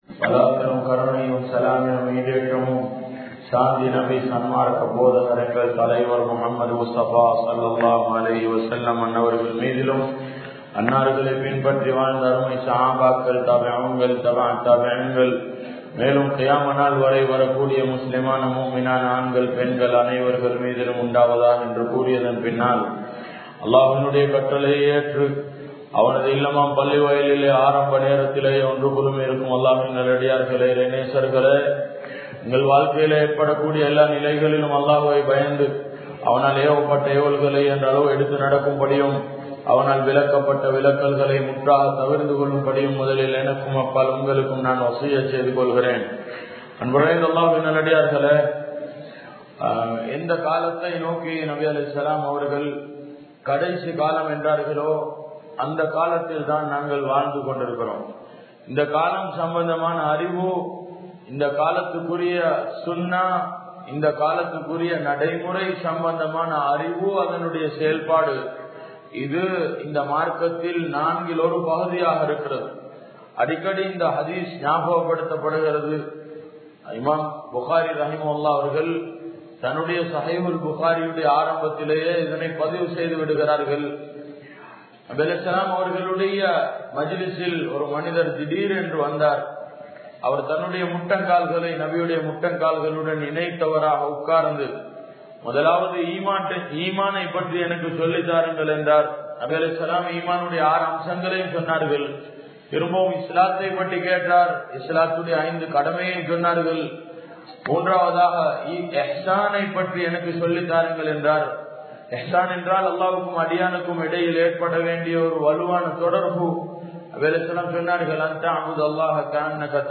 Inthak Kaalathil Padikka Vendiya Ilm Ethu?(இந்தக் காலத்தில் படிக்க வேண்டிய இல்ம் எது?) | Audio Bayans | All Ceylon Muslim Youth Community | Addalaichenai
Dehiwela, Muhideen (Markaz) Jumua Masjith